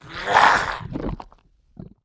attack_act_1.wav